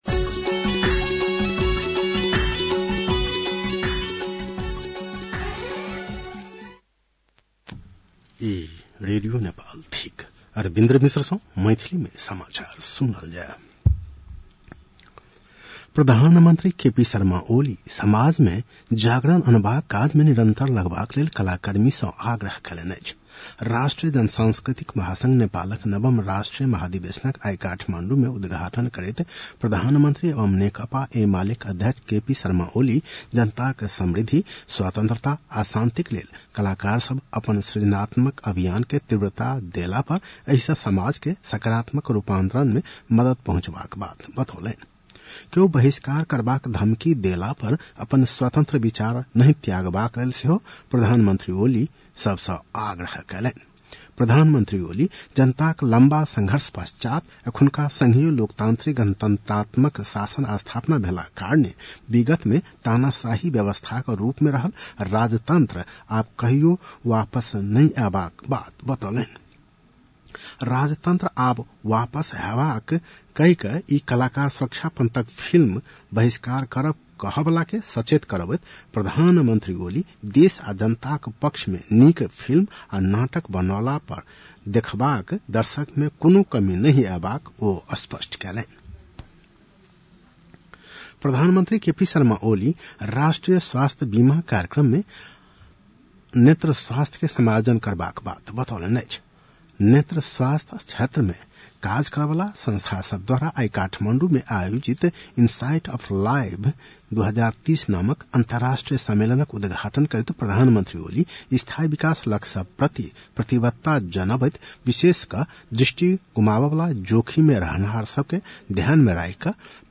मैथिली भाषामा समाचार : १७ वैशाख , २०८२